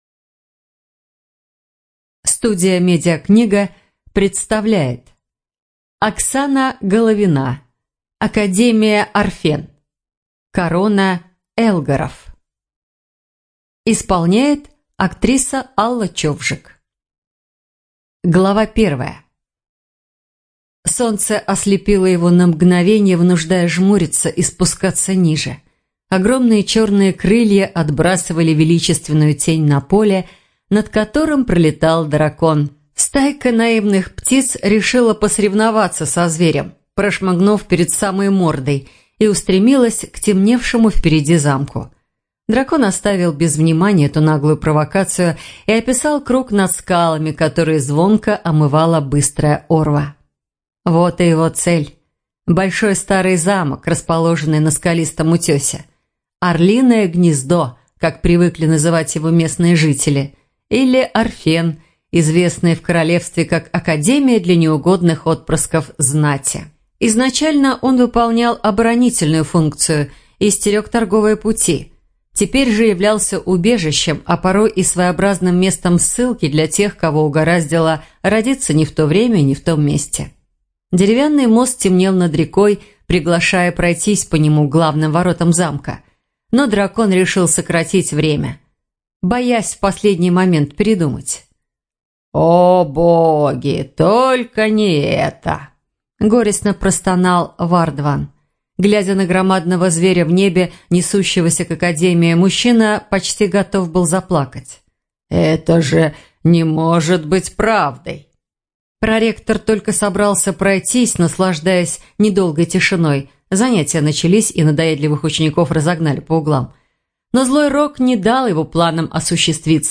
Студия звукозаписиМедиакнига